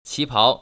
[qípáo] 치파오  ▶